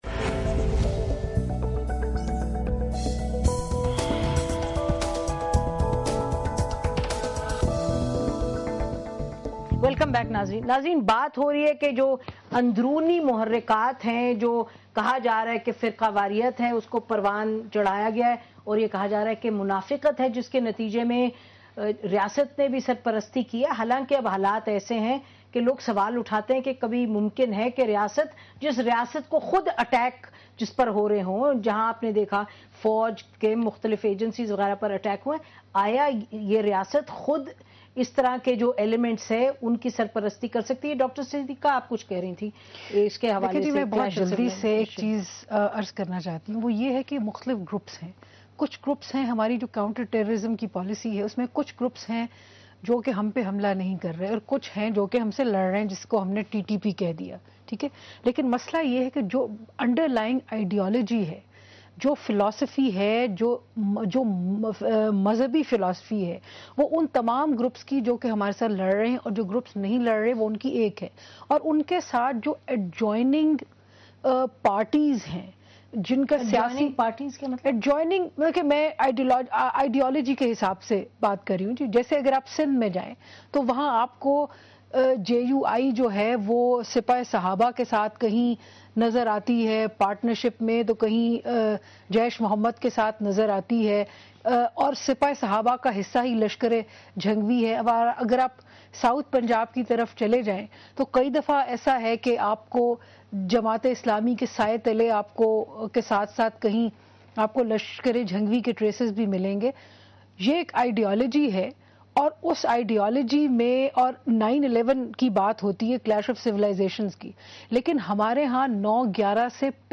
Category: TV Programs / Dunya News / Questions_Answers /
Javed Ahmad Ghamidi gives his views about "Terrorist Attack on Abdullah Shah Ghazi Shrine " in Dunya TV's program Policy Matter with Naseem Zahra.